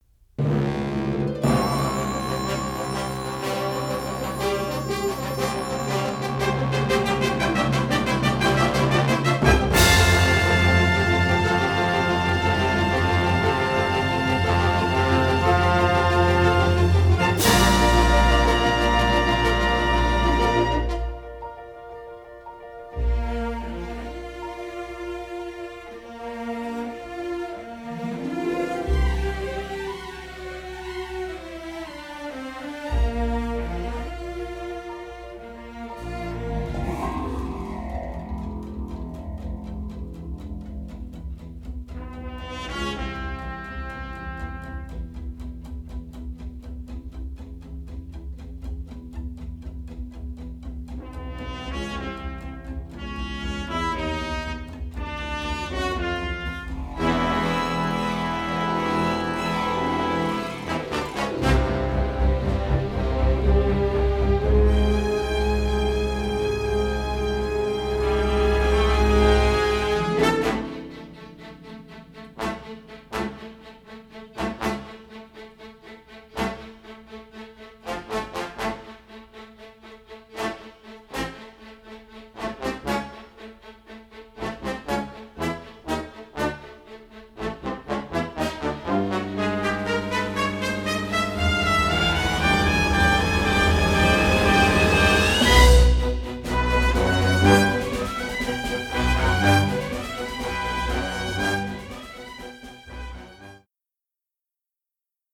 all in splendid stereo sound.
orchestra